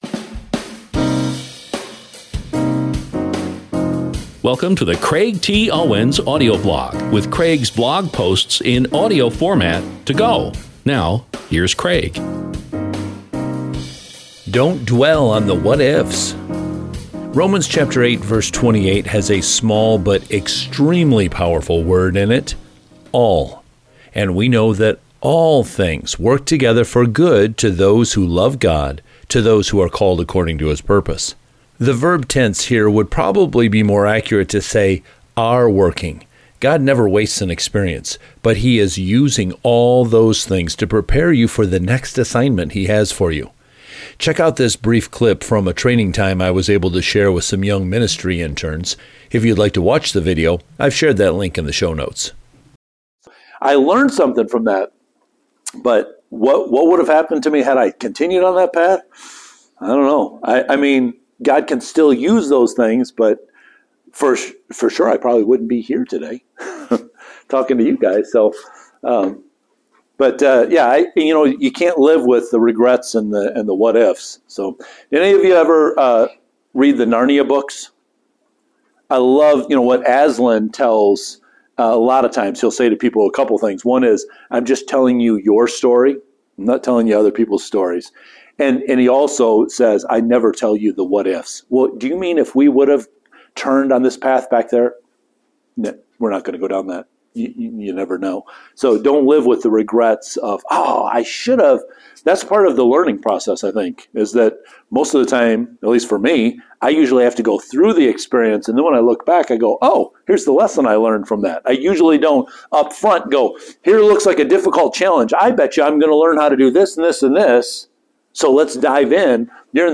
Check out this brief clip from a training time I was able to share with some young ministry interns.